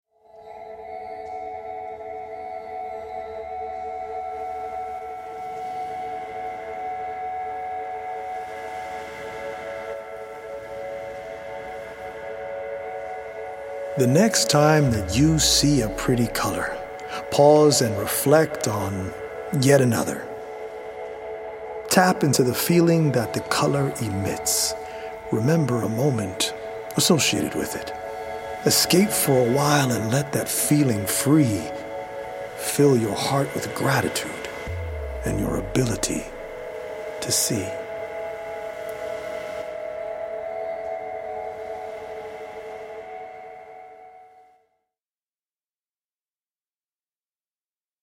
healing Solfeggio frequency music
EDM